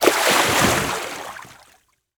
WaterSplash_BW.54167.ogg